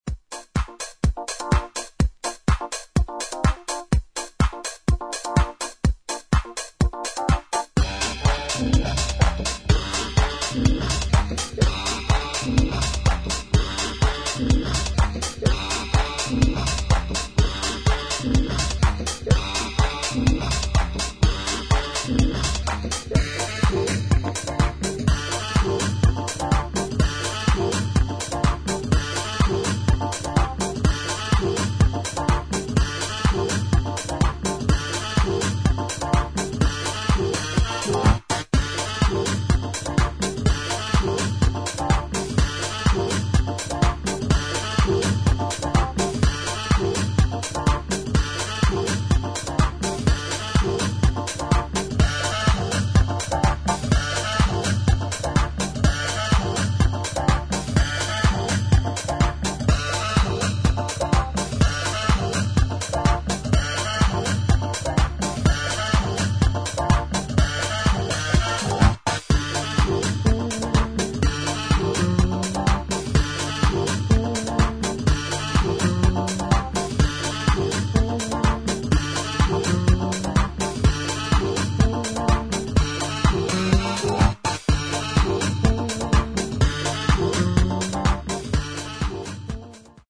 有機的な音と電子音の印象的な融合をハウスの領域で表現した一枚